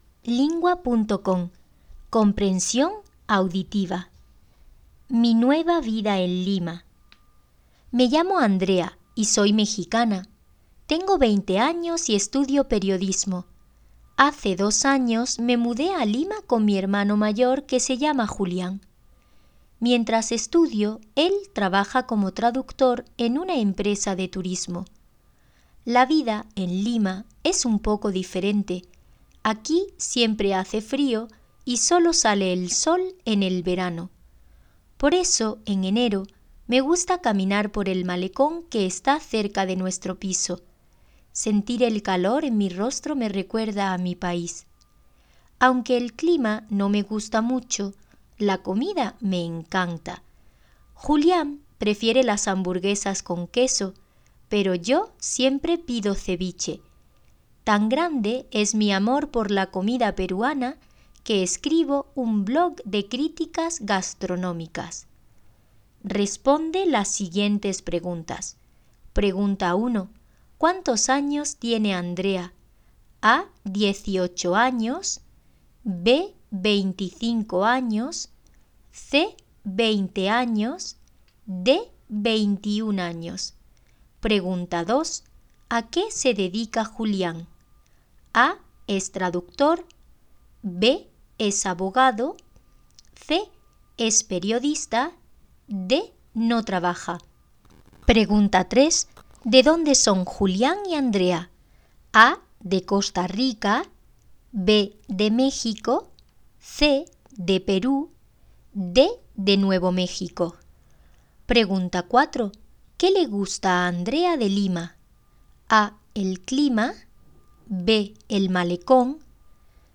Hiszpania